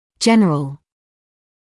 [‘ʤenrəl][‘джэнрэл]общий; повсеместный